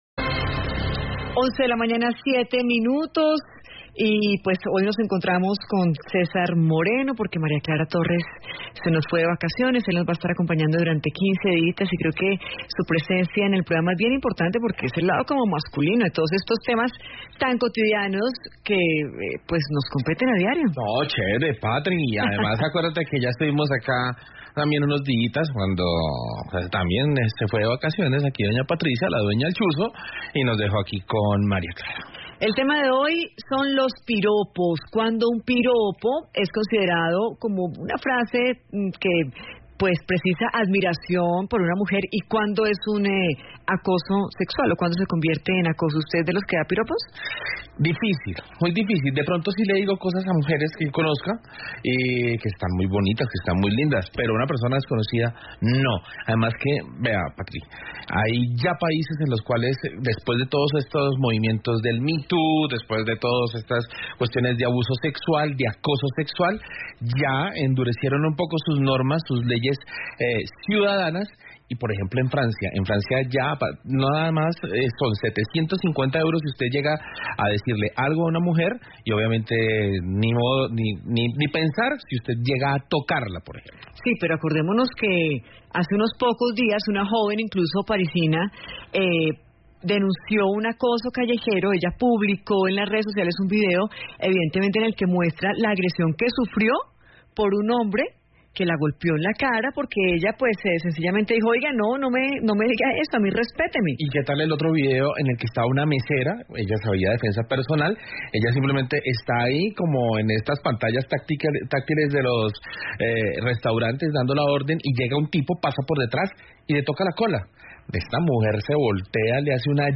¿Declarar el piropo como una expresión de violencia contra la mujer es realmente exagerar? En el debate planteado por Caracol Radio se discutió en torno a la percepción de seguridad de las mujeres en la calle, si existe vestimenta no adecuada para usar el transporte público y si abordar a un extraño en la calle para decirle piropos es acoso.